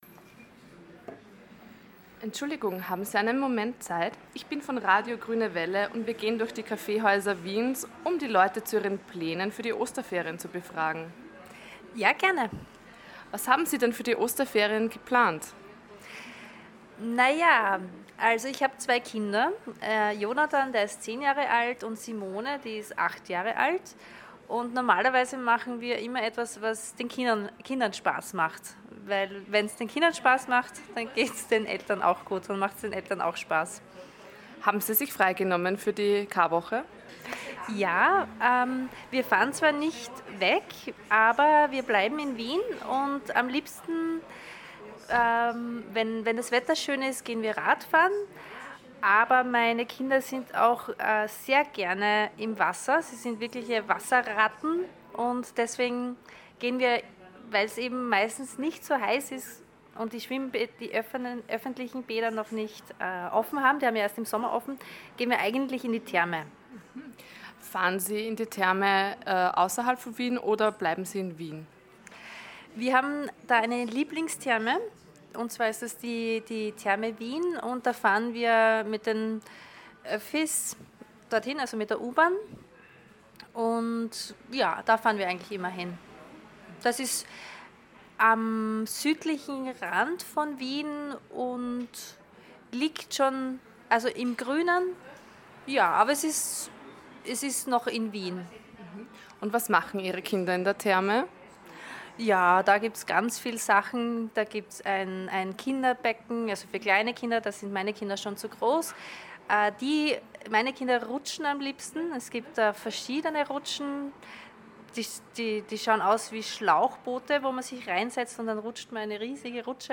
Die Sprecher und Sprecherinnen sprechen dann schneller, sie verwenden mehr Umgangssprache, sie verschlucken einige Silben und beenden ihre Sätze gar nicht richtig.